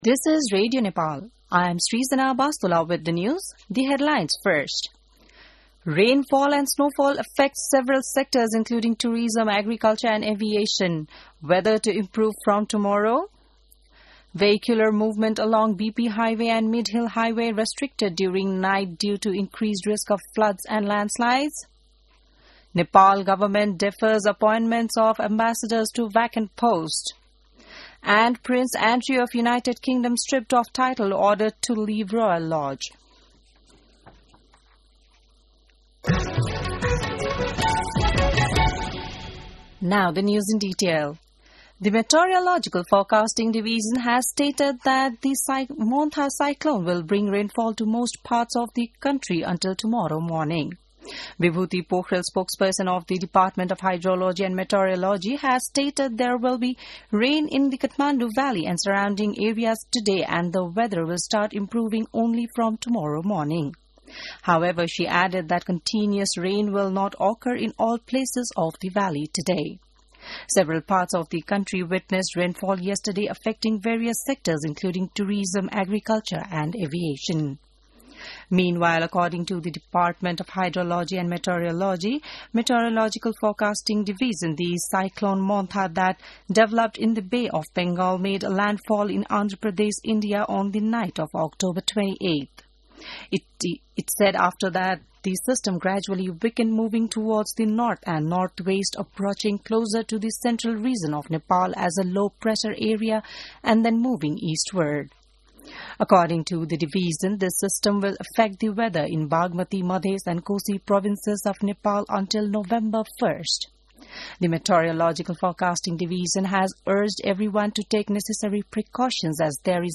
बिहान ८ बजेको अङ्ग्रेजी समाचार : १४ कार्तिक , २०८२